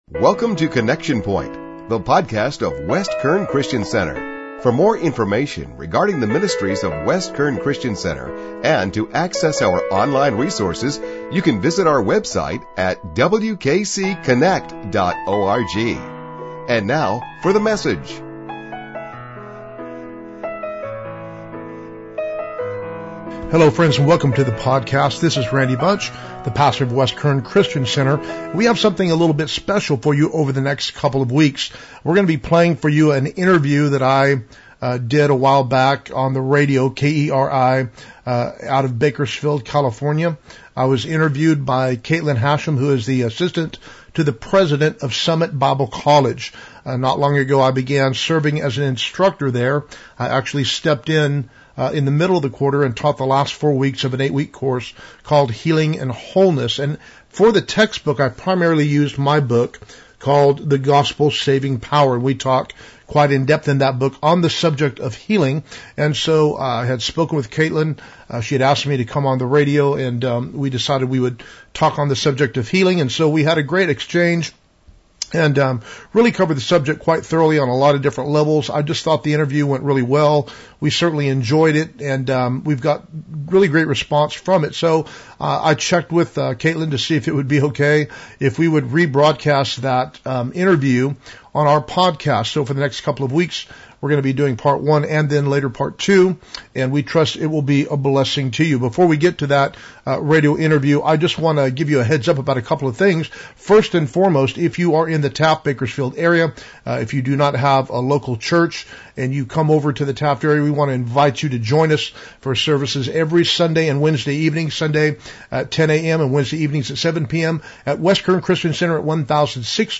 Summit Bible College Interview – Part 1
summit-bible-college-radio-interview-part-1.mp3